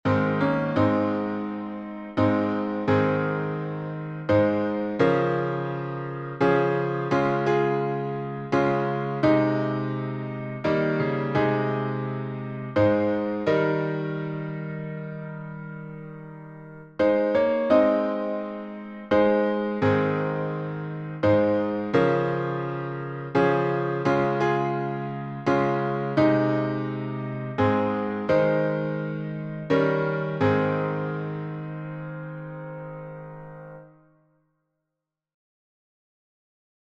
Key signature: A flat major (4 flats) Time signature: 6/4 Meter: 8.6.8.6.(C.M.)